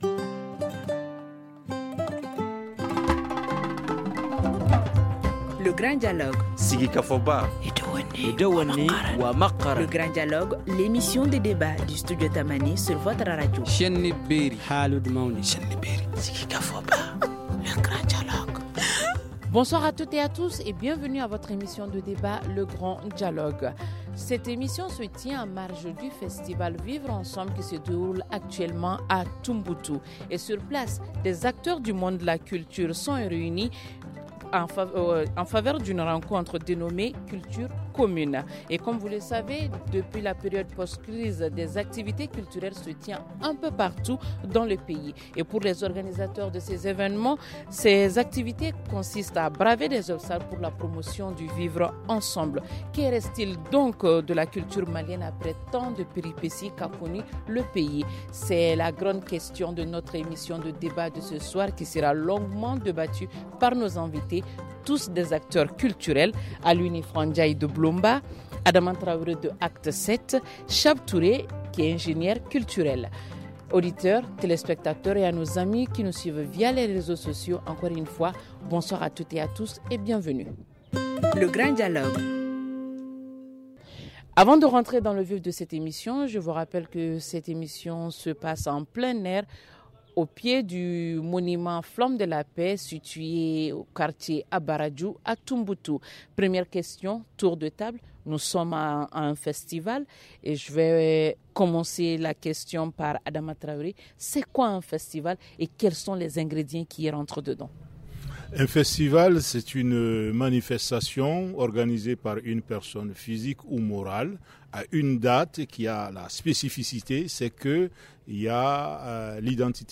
Que reste-il de la culture après tant de péripéties qu’a connu le pays ? Cette question sera longuement débattue au cours de cette émission de débat.